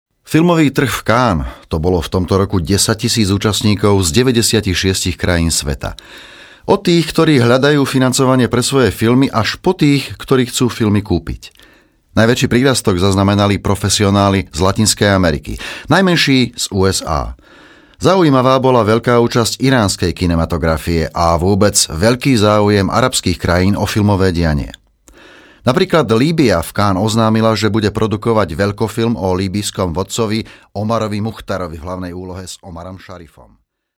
Professioneller slovakischer Sprecher für TV / Rundfunk / Industrie.
Sprechprobe: Werbung (Muttersprache):
Professionell voice over artist from Slovakia.